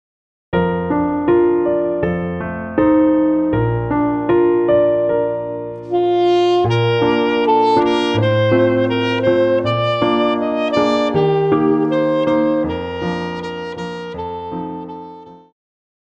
古典
中音萨克斯风
乐团
童谣,经典曲目,传统歌曲／民谣
演奏曲
独奏与伴奏
有主奏
有节拍器
This is an arrangement for saxophone and piano.